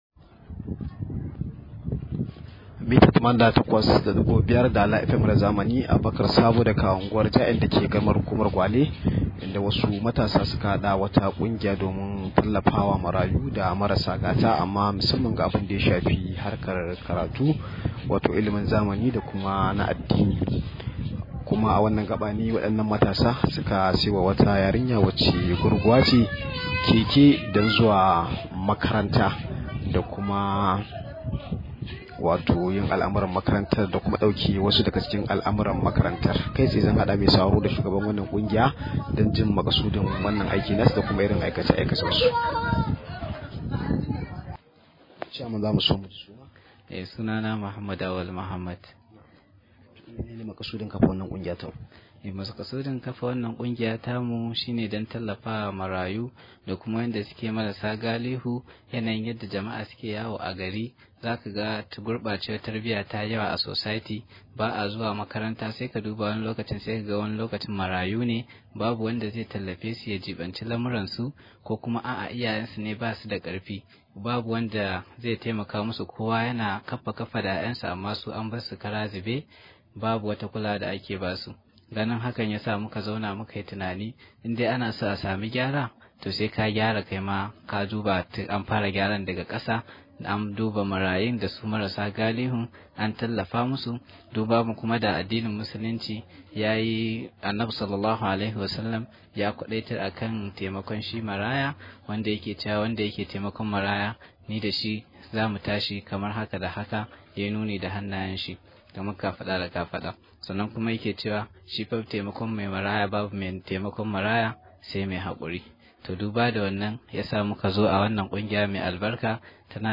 Rahoto: Kungiyar matasan Ja’en ta tallafawa yarinya da Keken Guragu